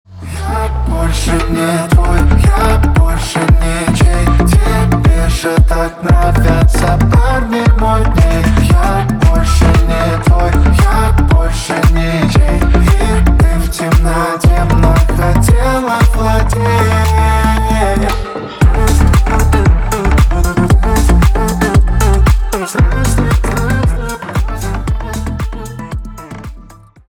поп
Club House